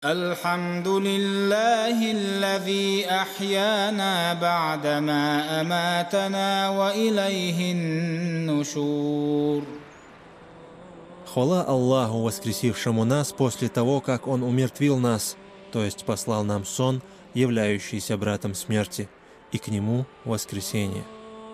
Звуки дуа после сна
На этой странице вы можете скачать и слушать дуа после сна – исламскую молитву, читаемую при пробуждении. Это благодарность Аллаху за новый день и возобновление жизненных сил.